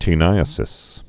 (tē-nīə-sĭs)